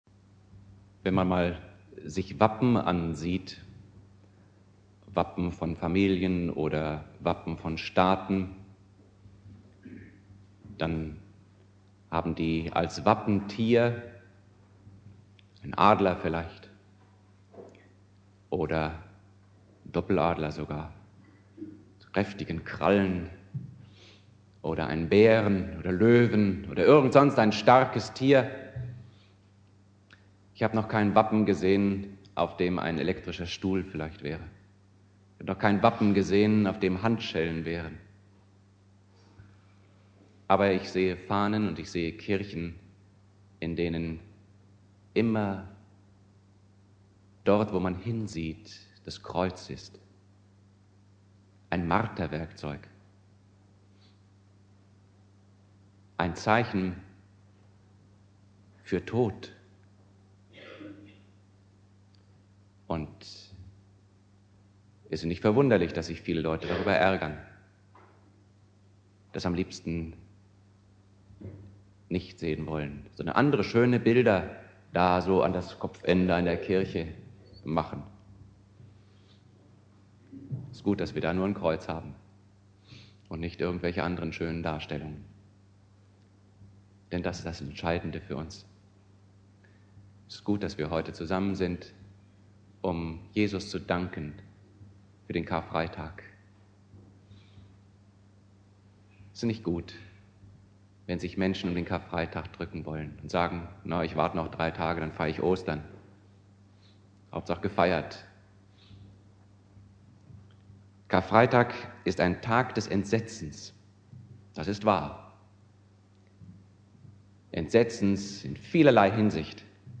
Predigt
Karfreitag